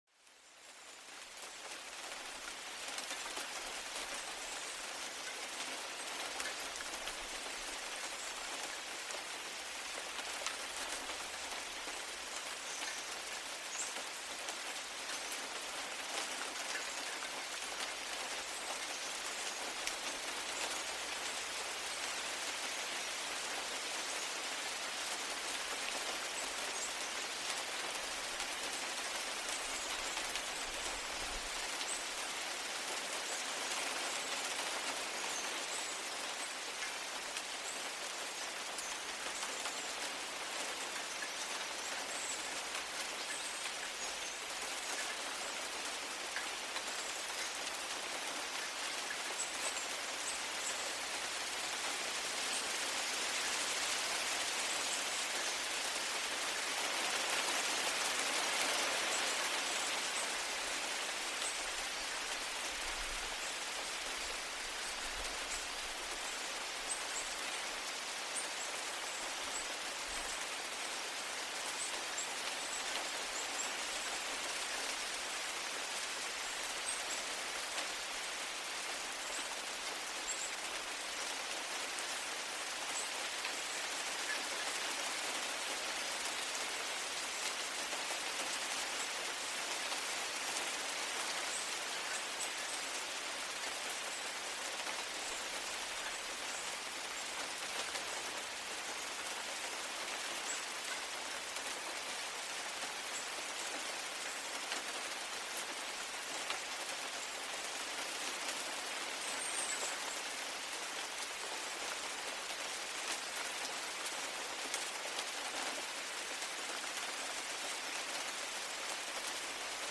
rain_quiet.CggSOQGK.mp3